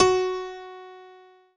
PIANO5-08.wav